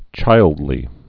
(chīldlē)